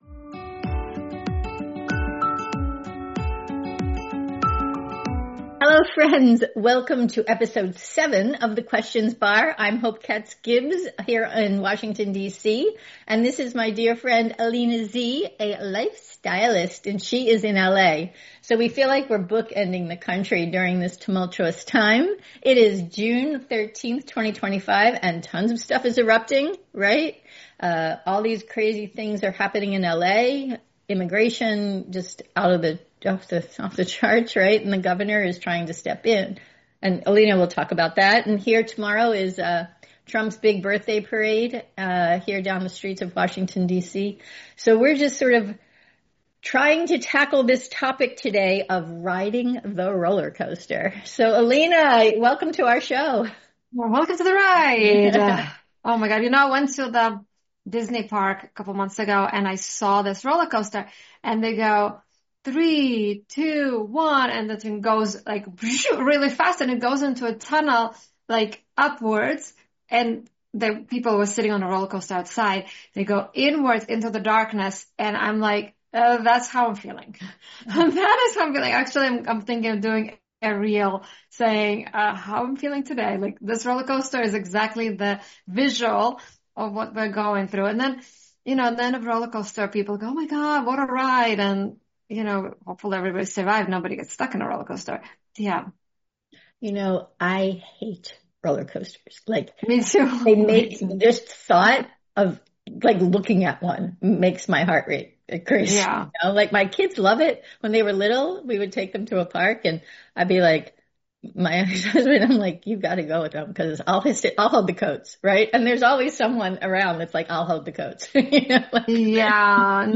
a chat with two girlfriends talking about topics of the day